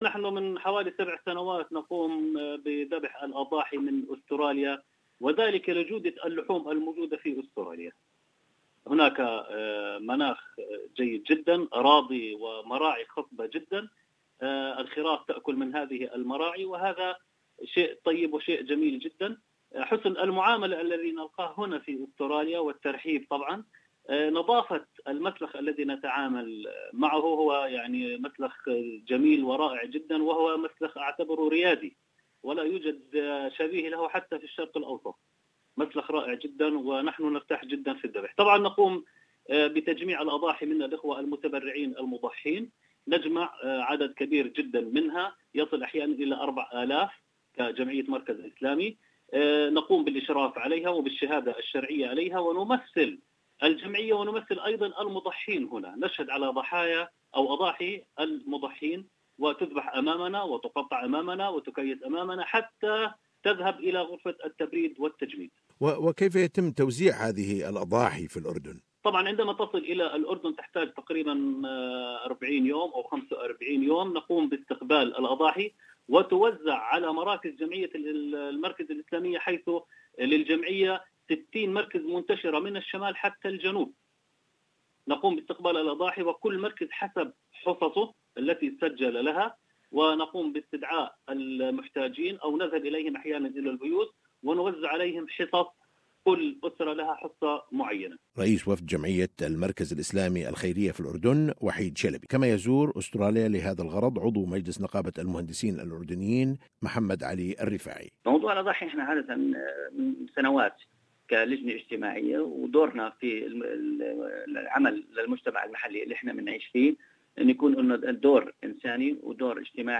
Visiting Australia currently several delegations representing Jordanian charity organizations and associations to oversee implementation of Udhiyah project which benefit poor families in Jordan and Palestine. Interview with Jordanian delegations .